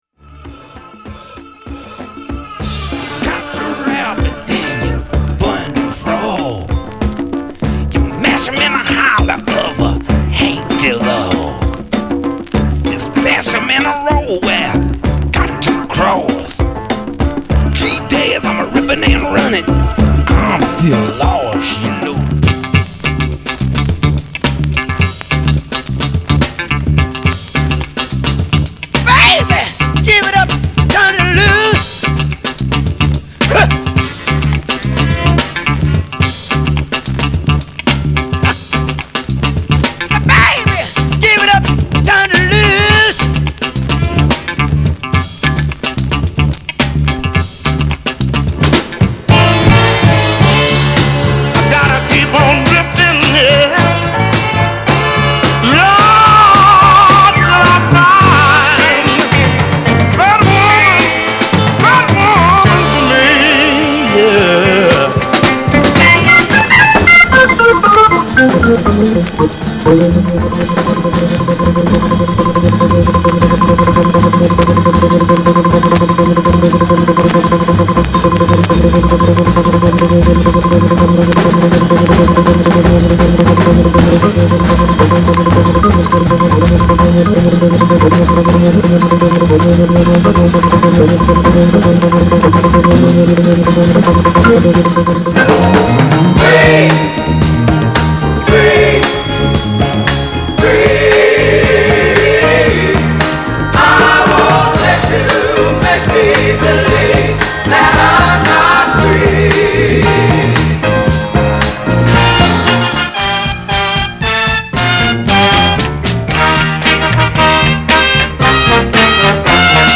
当前位置 > 首页 >音乐 >唱片 >R＆B，灵魂